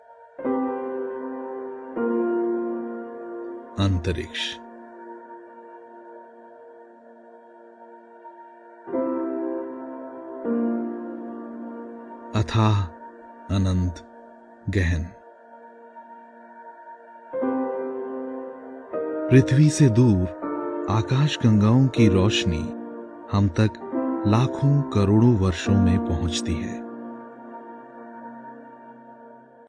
Contemporary and modern. Earthy, modest and deep style.
Audio Book